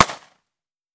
9mm Micro Pistol - Gunshot A 003.wav